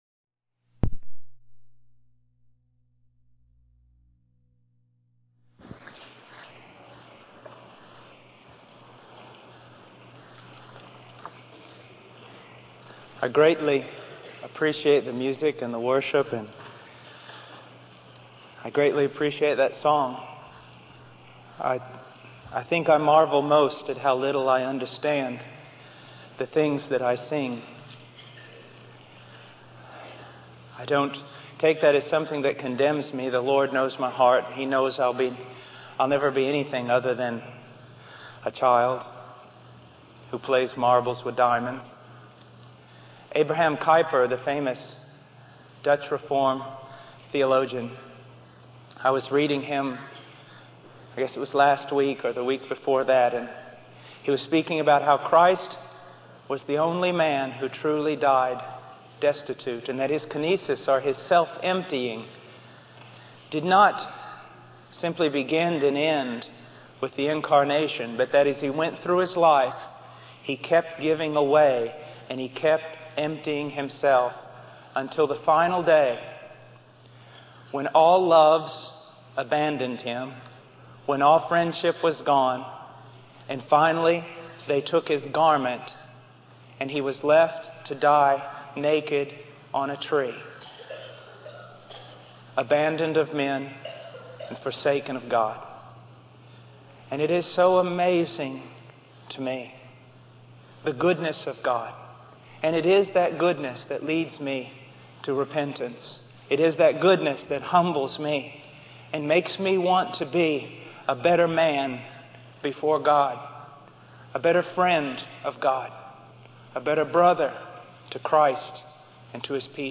In this sermon, the preacher shares a personal story about a baptism in Evansville, Indiana.